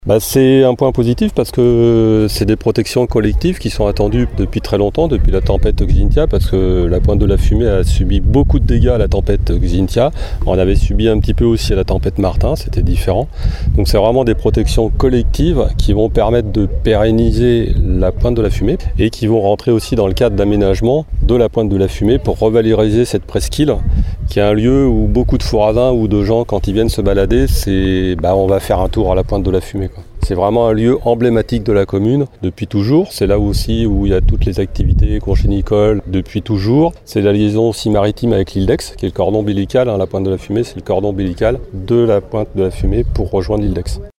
Un préalable aux travaux de réhabilitation de la pointe de la fumée qui vont démarrer cet automne. Des travaux très attendus, comme le souligne Éric Simonin, adjoint au maire de Fouras, en charge de la protection du littoral et de l’environnement :